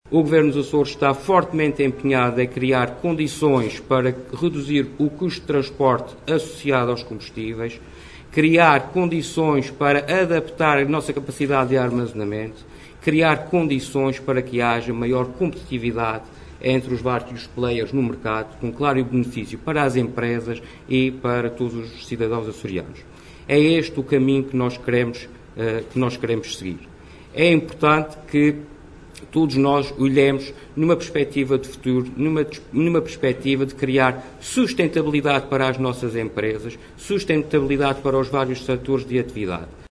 Na intervenção feita durante a cerimónia de apresentação da renovação da rede de postos de abastecimento da Repsol na ilha de S. Miguel, Vítor Fraga adiantou que o executivo açoriano quer também “criar condições para que haja maior competitividade” entre os vários intervenientes no mercado, o que trará “claro benefício para as empresas e para todos os cidadãos açorianos”.